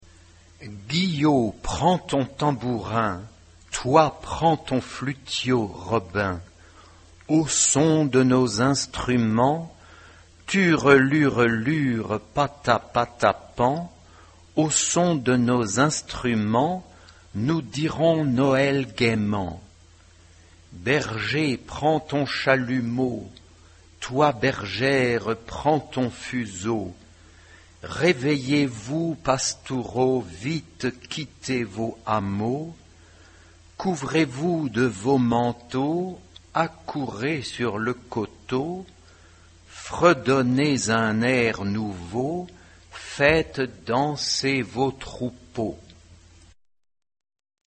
Noël bourguignon pour la tribu des Paowas ...
Genre-Style-Forme : Sacré ; Profane ; Populaire ; noël Type de choeur : SATB (4 voix mixtes )
Tonalité : la mineur